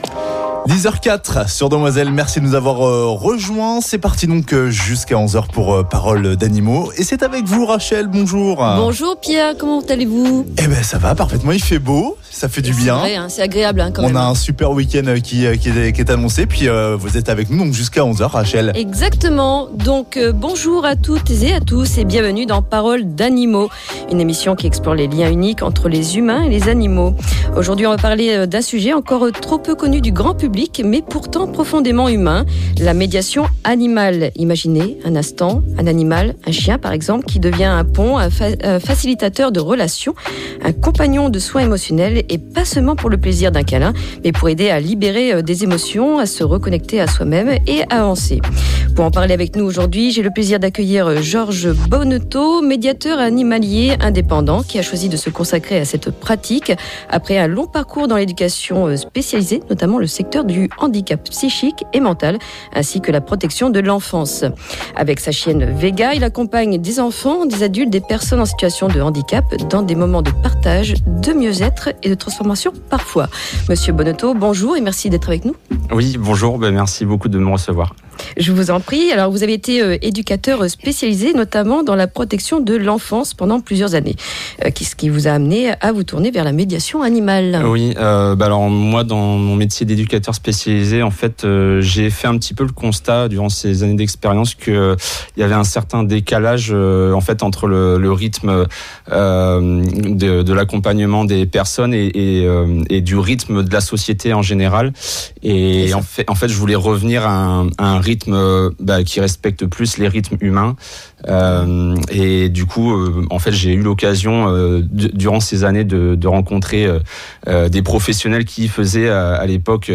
Et puis, dans la "News info", interview de différents intervenants lors de la "Journée cohésion" au collège Beauregard de La Rochelle. Une journée dédiée à la protection de l'environnement.